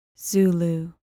Pronounced: ZOO-loo